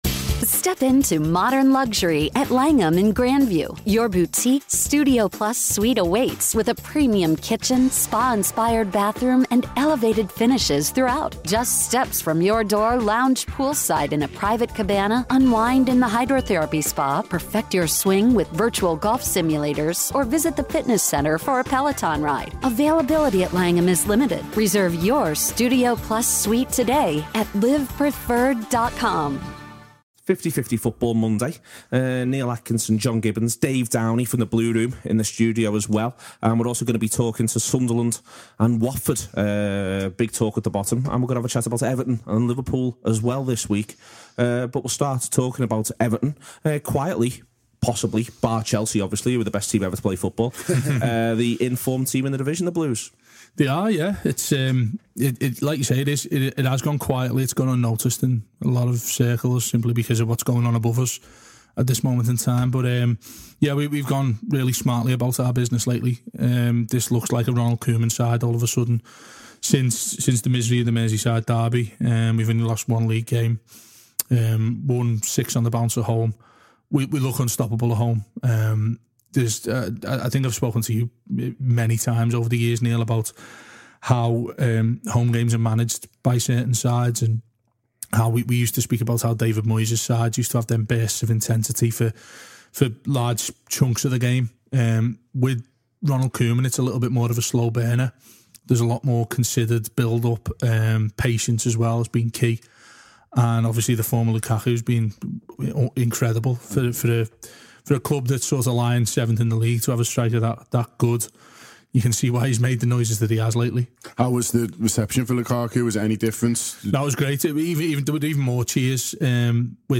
We analyse an exhilarating game of football and what it says about the strengths and weaknesses of both sides. On the phone